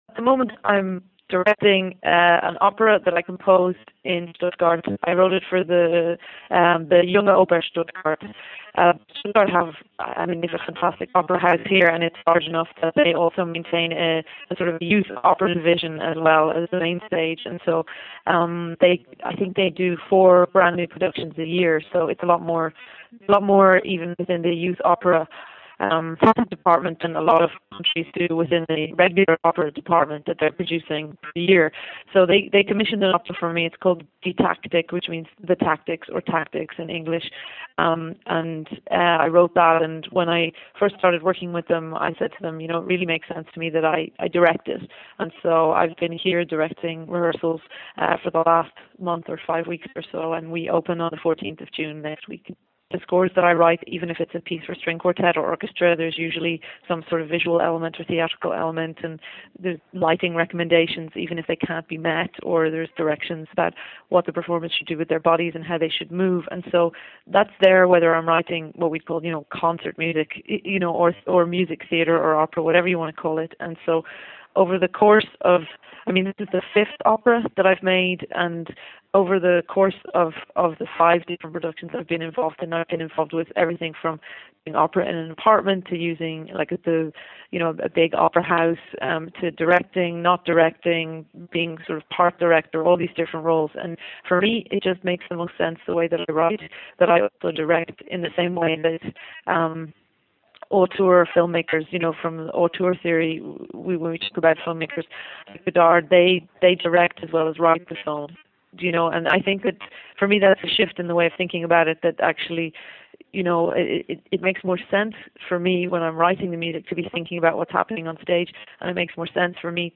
Interview with Jennifer Walshe